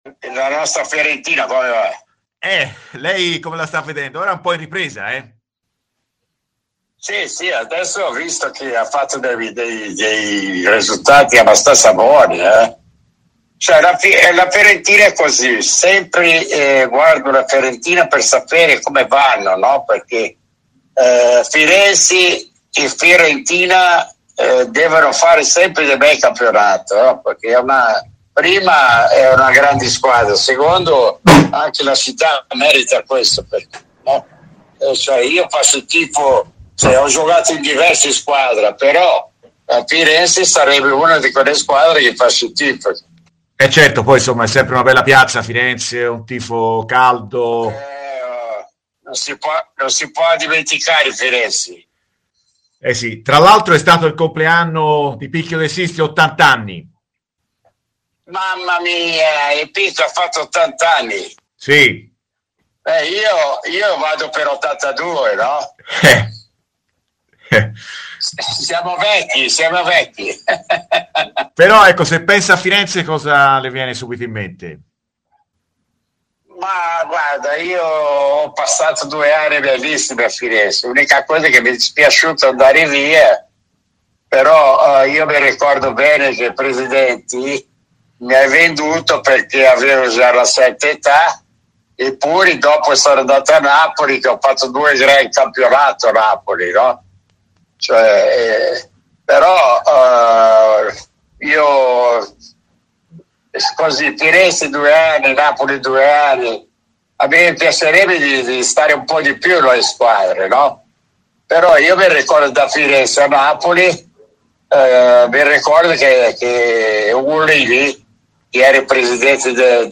Sergio Clerici, soprannominato il Gringo, ex attaccante viola negli anni '70, è intervenuto dal Brasile ai microfoni di Radio FirenzeViola, trattando in principali temi in casa viola.